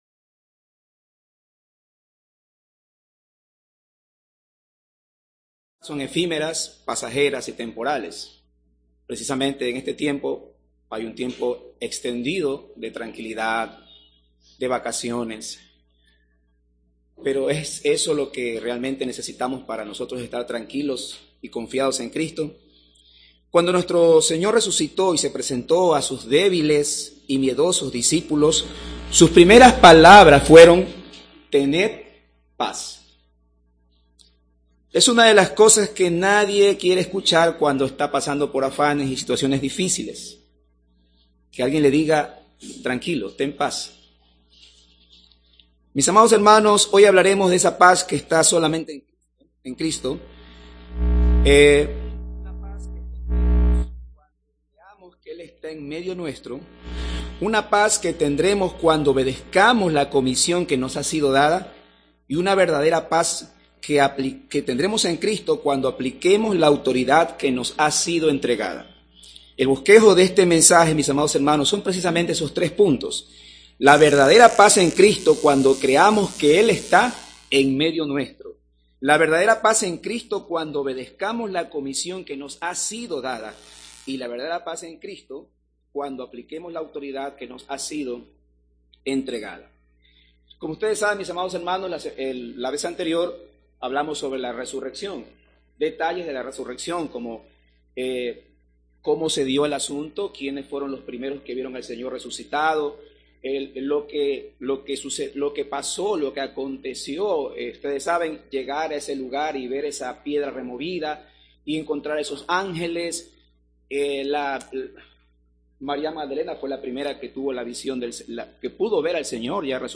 Audio del sermón